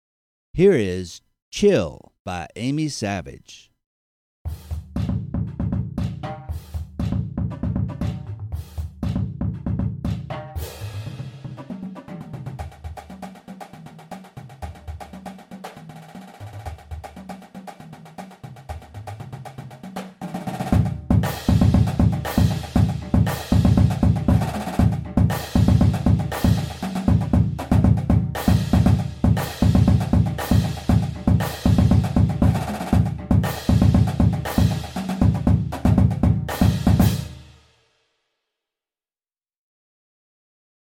Voicing: Percussion Feature